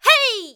qyh长声1.wav 0:00.00 0:00.56 qyh长声1.wav WAV · 48 KB · 單聲道 (1ch) 下载文件 本站所有音效均采用 CC0 授权 ，可免费用于商业与个人项目，无需署名。